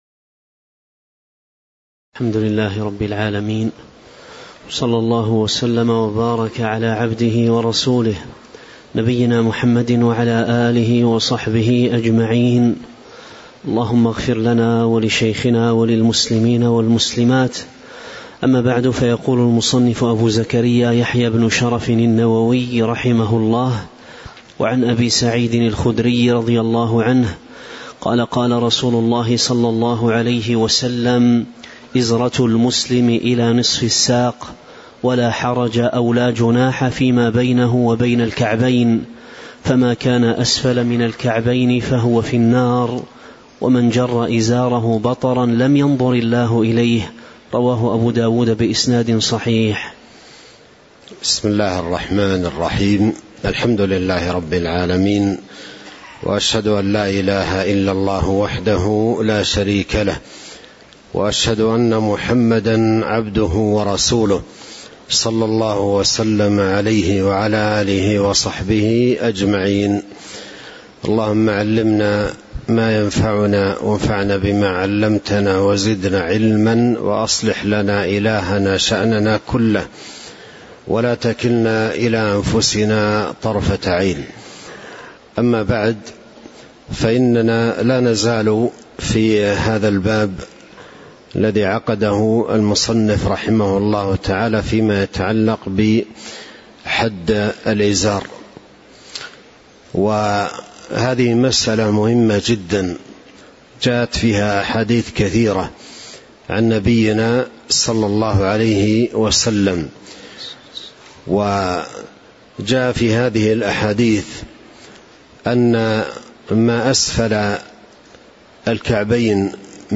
تاريخ النشر ١٩ صفر ١٤٤٥ هـ المكان: المسجد النبوي الشيخ: فضيلة الشيخ عبد الرزاق بن عبد المحسن البدر فضيلة الشيخ عبد الرزاق بن عبد المحسن البدر باب صفة طول القميص والكم والإزار (05)